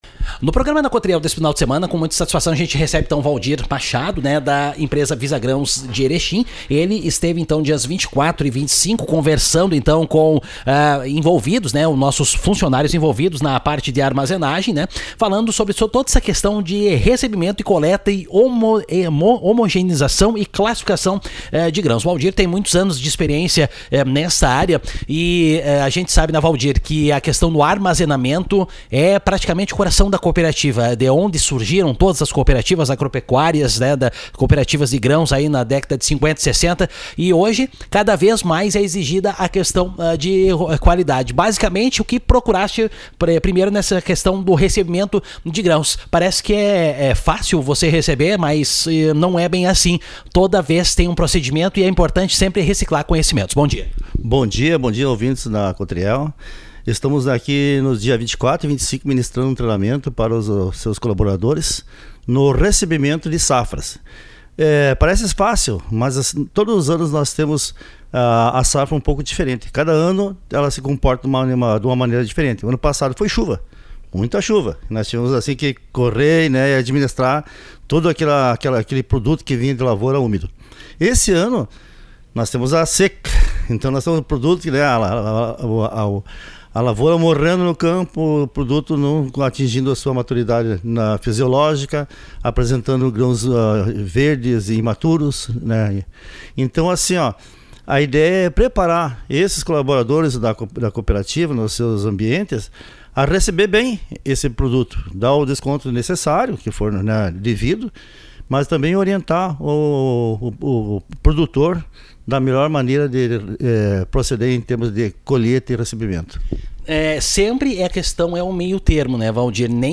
Ouça aqui a entrevista completa